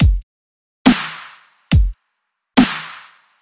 杜比斯特舞曲循环
描述：胖胖的dubstep鼓声。
标签： 140 bpm Dubstep Loops Drum Loops 590.80 KB wav Key : Unknown
声道立体声